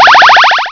startgame.wav